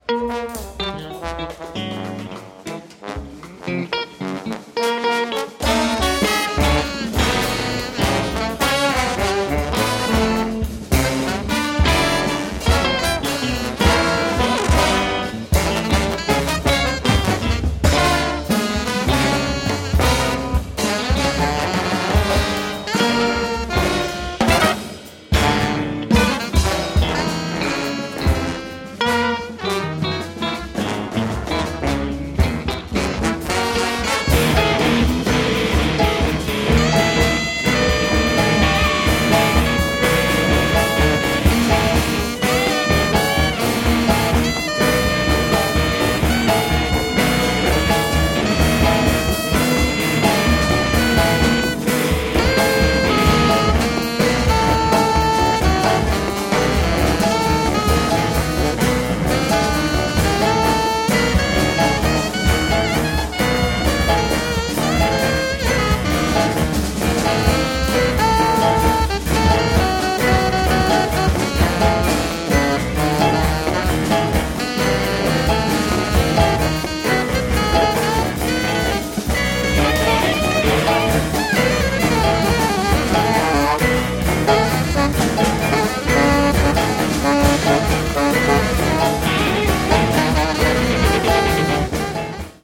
trumpet
violin
alto saxophone
trombone
bass saxophone
piano
percussion
drums
electric guitar
& at Salle Paul Fort, Nantes, France on November 21, 2012.
The 12-piece-band live recordings